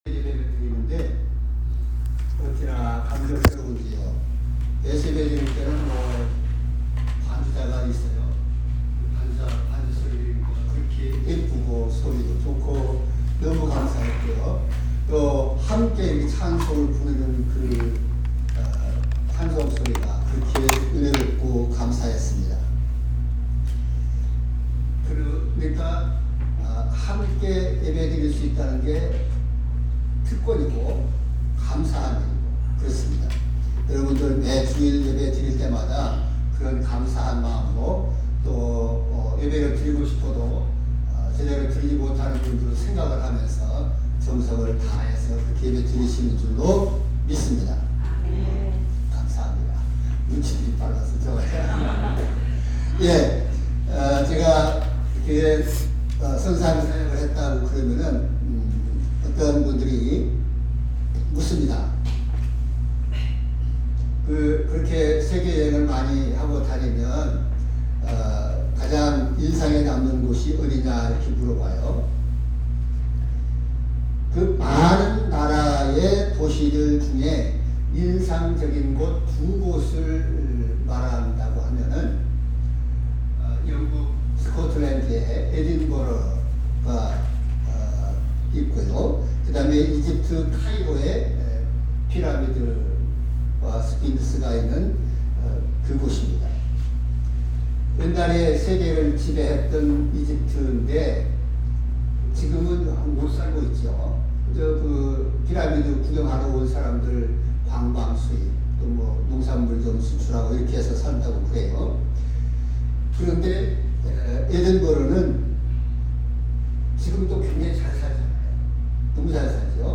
말씀